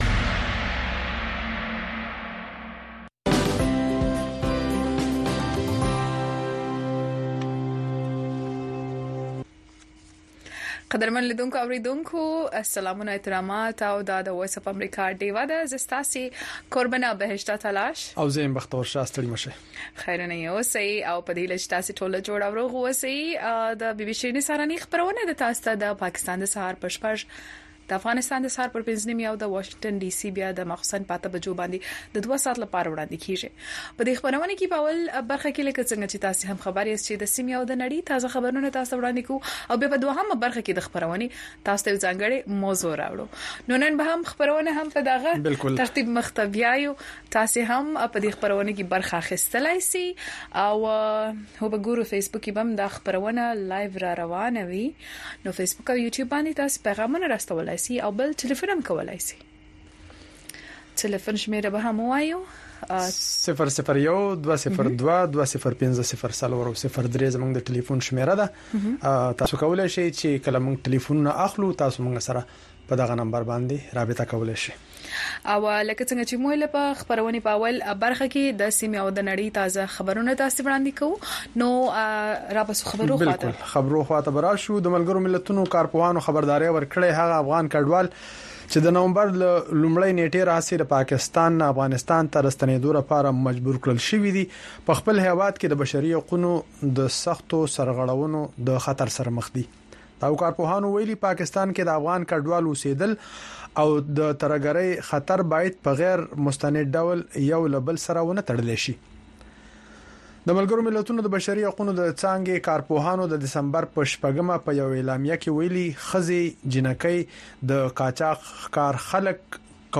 د وی او اې ډيوه راډيو سهرنې خبرونه چالان کړئ اؤ د ورځې د مهمو تازه خبرونو سرليکونه واورئ.